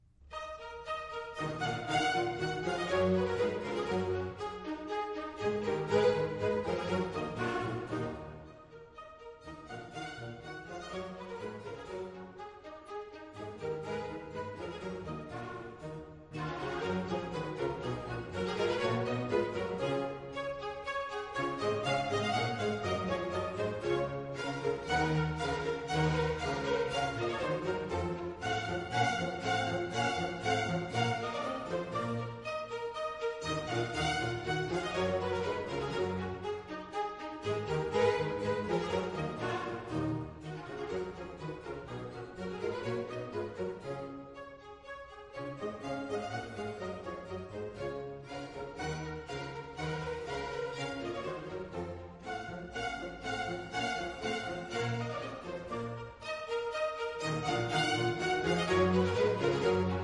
Ballet : Musette